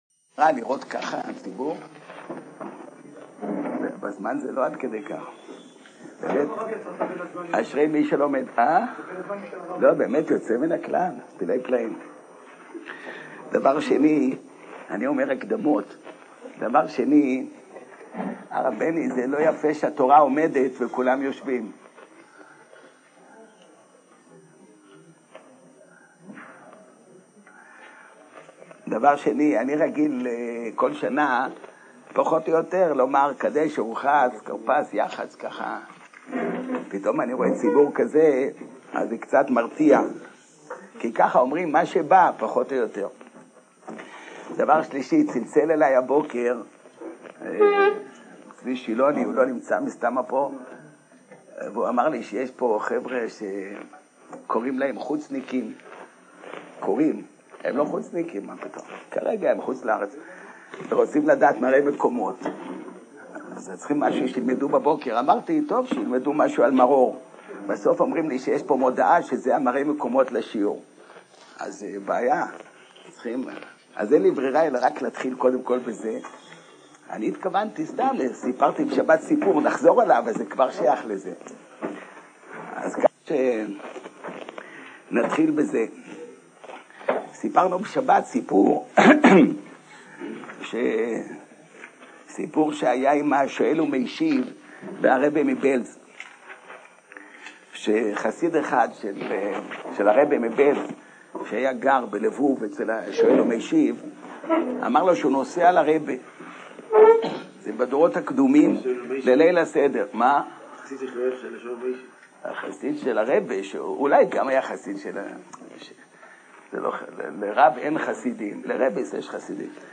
תשס"א להאזנה לשיעור: https